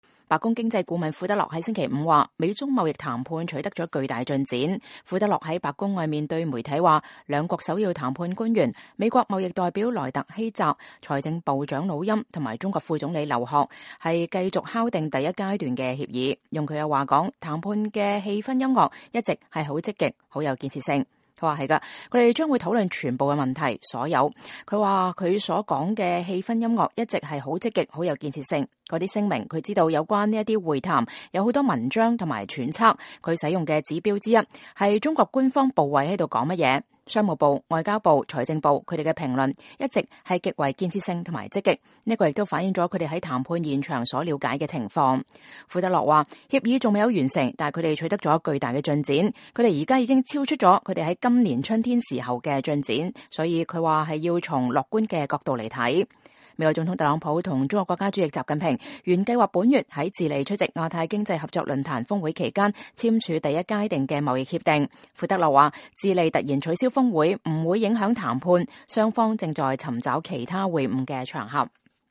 白宮經濟顧問庫德洛在白宮外對媒體講話。